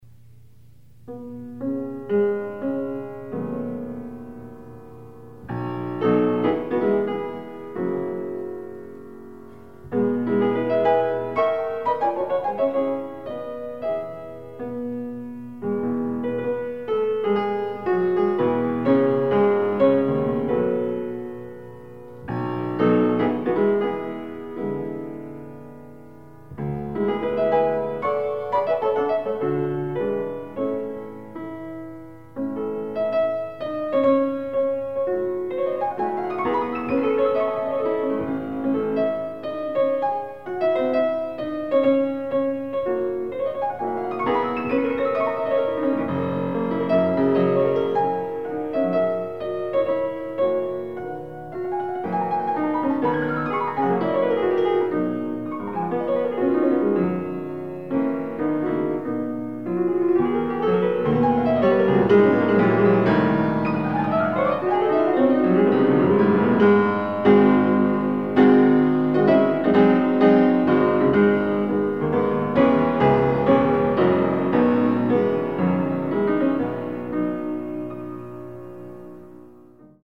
Additional Date(s)Recorded September 15, 1977 in the Ed Landreth Hall, Texas Christian University, Fort Worth, Texas
Scherzos
Short audio samples from performance